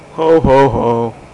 Ho Ho Ho Sound Effect
Download a high-quality ho ho ho sound effect.
ho-ho-ho.mp3